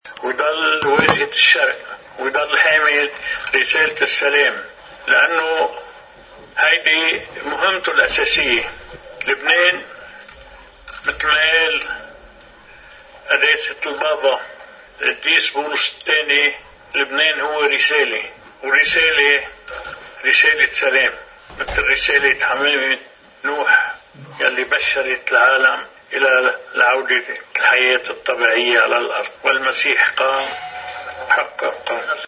مقتطف من حديث الرئيس عون من بكركي يوم عيد الفصح، إثر الخلوة مع البطريرك الراعي: